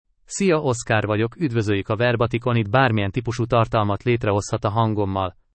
OscarMale Hungarian AI voice
Oscar is a male AI voice for Hungarian (Hungary).
Voice sample
Listen to Oscar's male Hungarian voice.
Male
Oscar delivers clear pronunciation with authentic Hungary Hungarian intonation, making your content sound professionally produced.